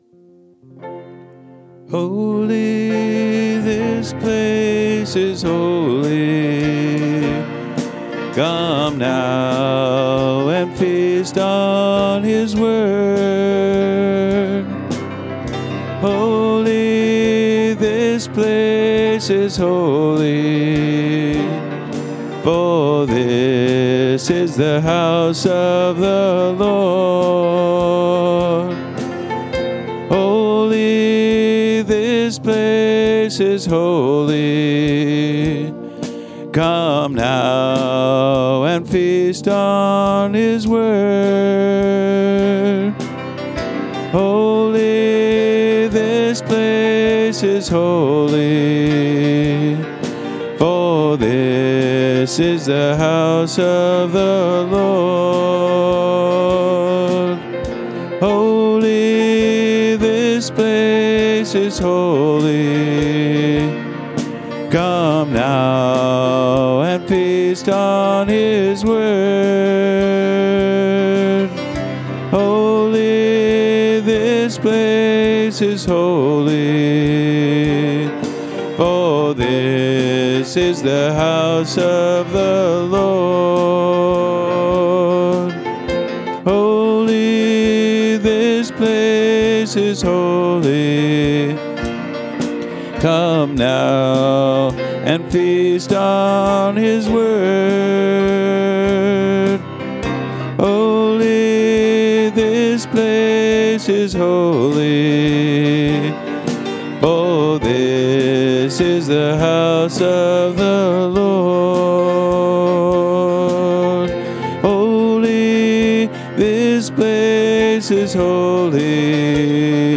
Sunday Service 11/27/22